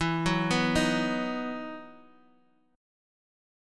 EM7sus2 Chord
Listen to EM7sus2 strummed